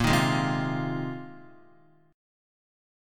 A#7sus2sus4 chord